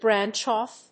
アクセントbránch óff